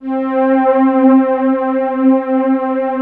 70 STRINGS-L.wav